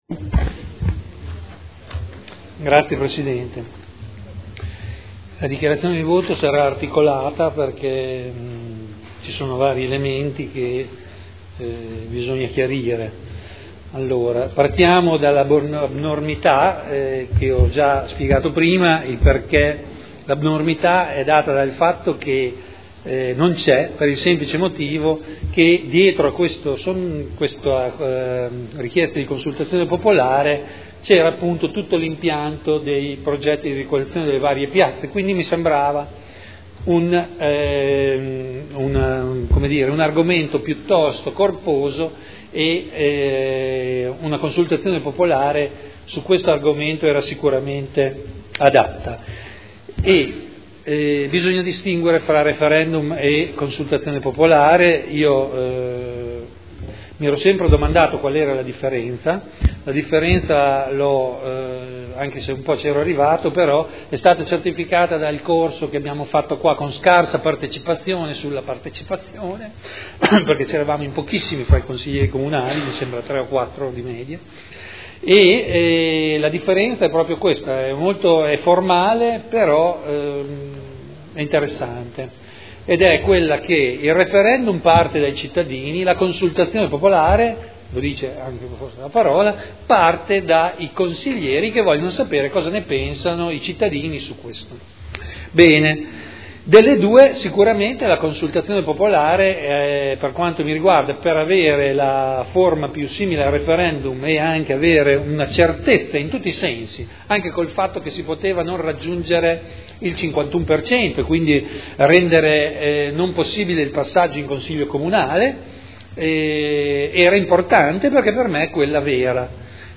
Vittorio Ballestrazzi — Sito Audio Consiglio Comunale
Seduta del 05/09/2011. Dichiarazione di voto su Consultazione popolare ai sensi dell’art. 8 del Regolamento degli Istituti di Partecipazione del Comune di Modena sul progetto di ristrutturazione di Piazza Matteotti mediante sondaggio (Conferenza Capigruppo del 5 settembre 2011)